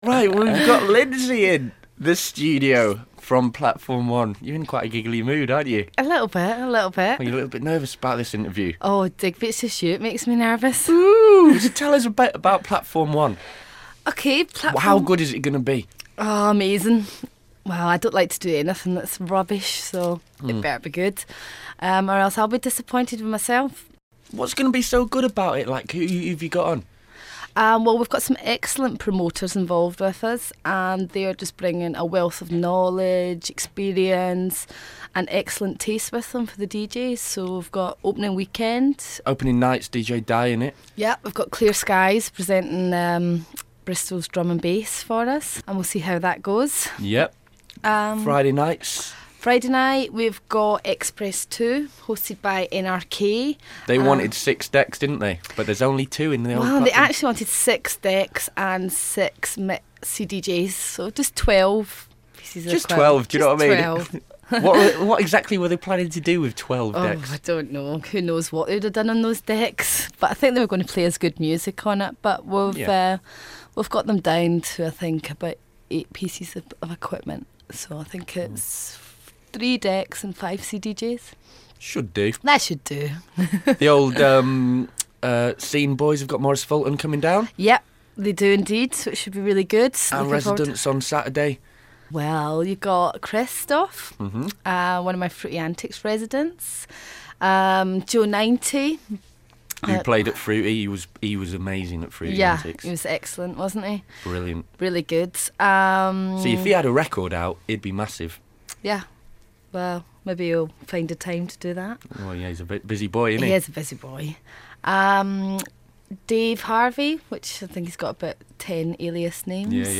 Music, interviews and gossip from Bristols best new club.